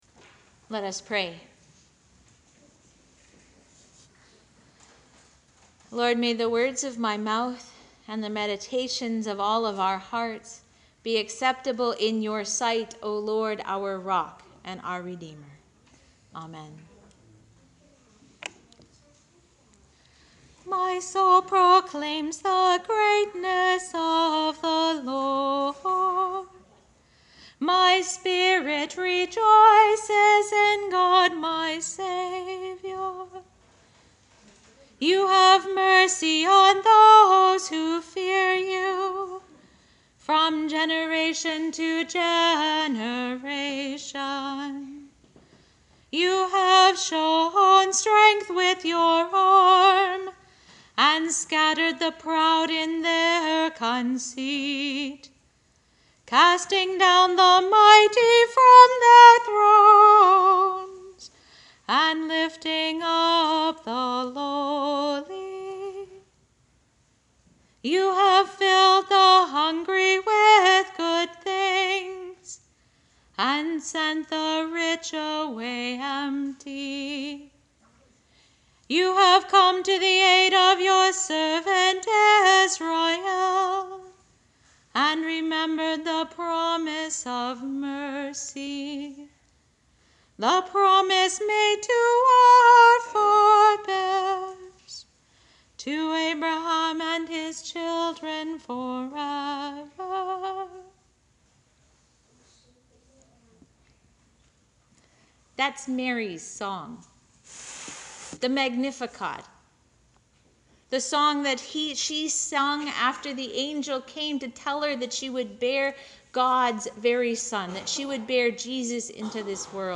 Link to the audio file of the sermon: Sermon on the Master and three slaves
sermon-for-last-sunday-in-pentecost.mp3